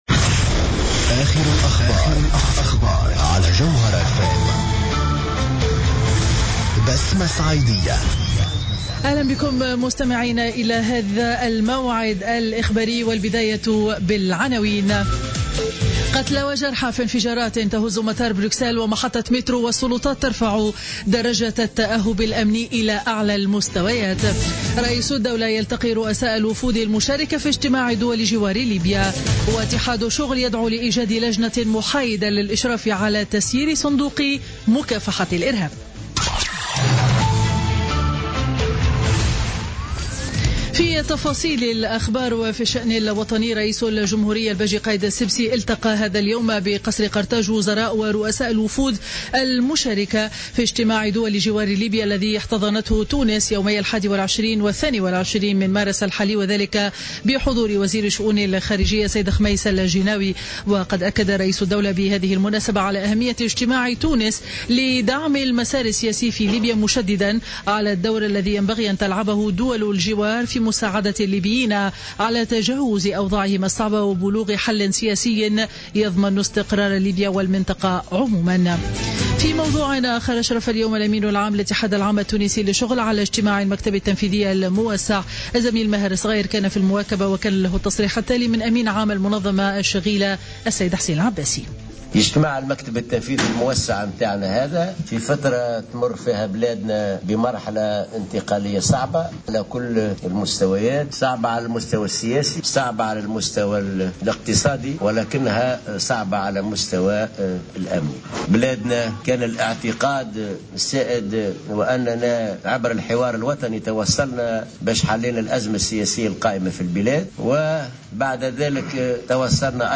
نشرة أخبار منتصف النهار ليوم الثلاثاء 22 مارس 2016